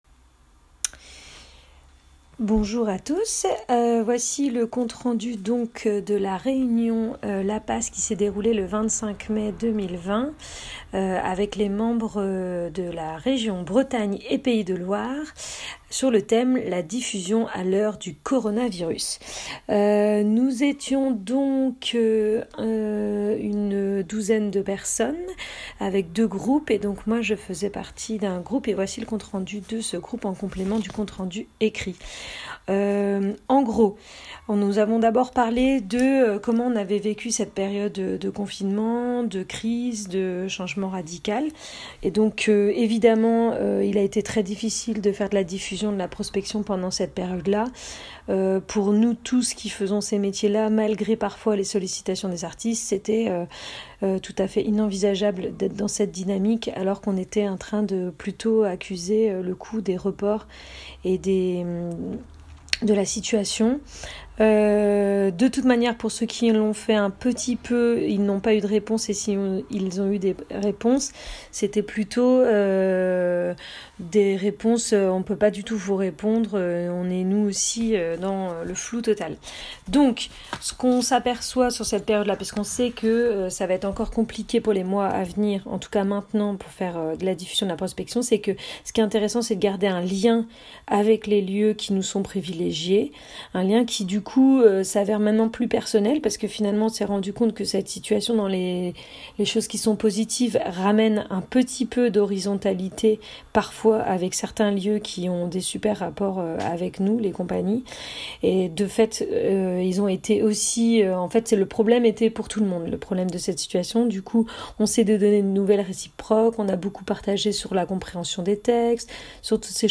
L’objectif de cette réunion est d’abord la réflexion collective.
25 mai 2020 – Rennes